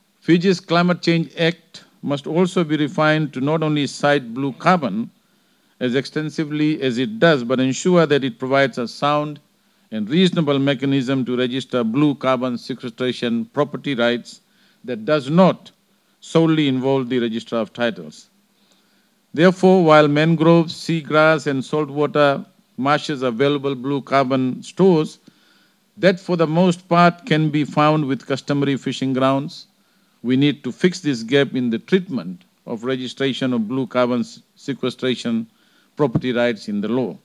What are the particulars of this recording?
Crisis Assessment (POCCA) in Nadi yesterday.